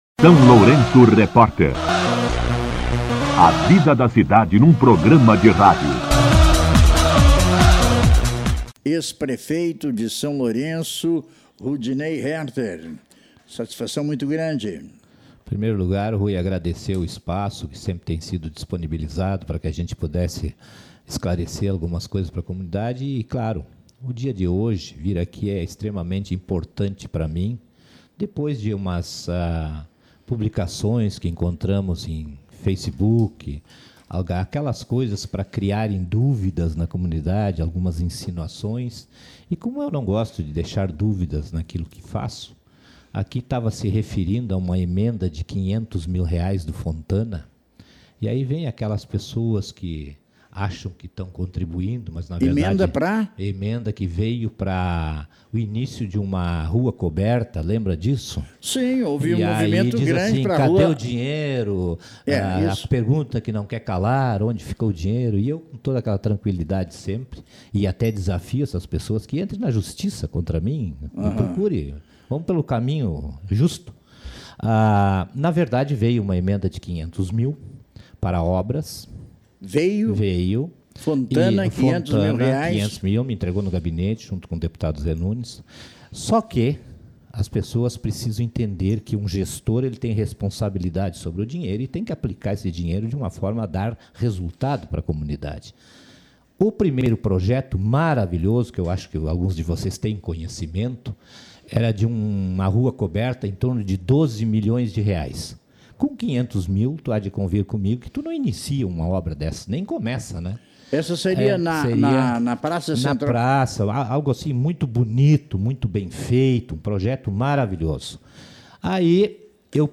Entrevista com o Ex-prefeito Rudinei Härter
O ex-prefeito municipal Rudinei Härter concedeu entrevista ao SLR RÁDIO na manhã desta terça-feira (24) para esclarecer a destinação de uma emenda parlamentar de R$ 500 mil, inicialmente prevista para o início das obras da Rua Coberta, na Praça Central.